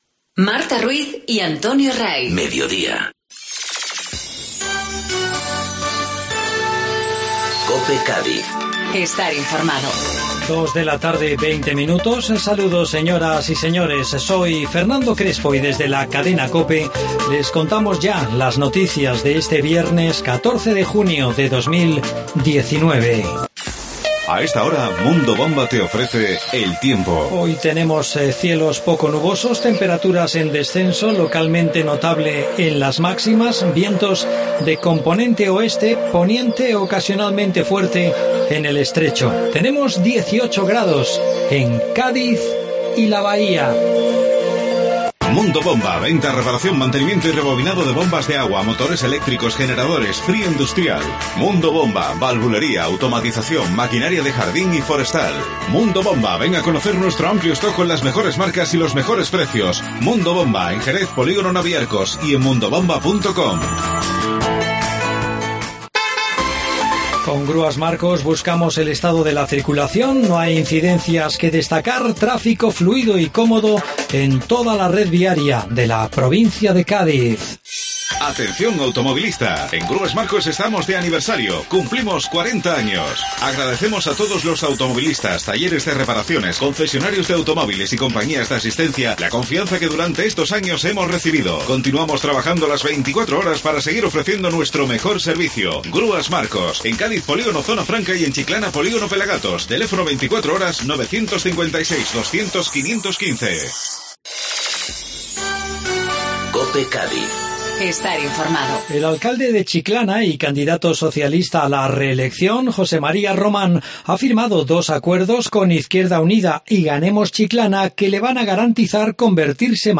Informativo Mediodía COPE Cádiz (14-6-19)